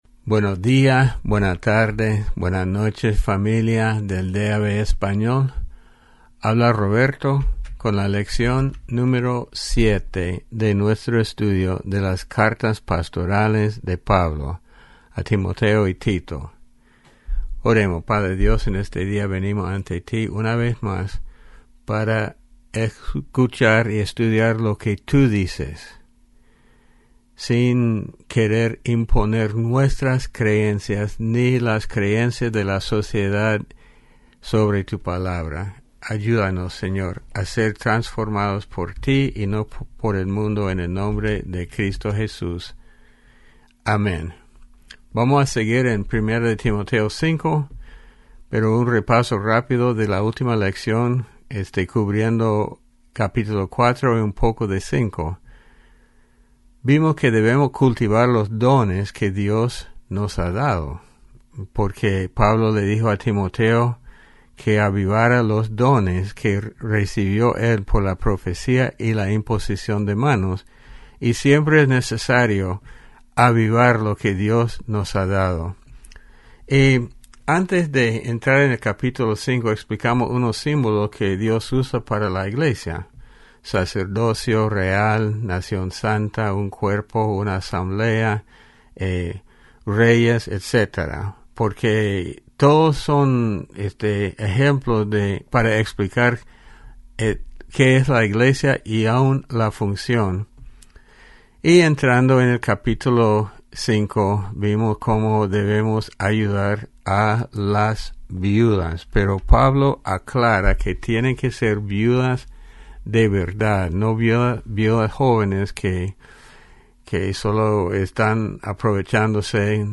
Lección 07 Las Cartas Pastorales (Timoteo y Tito)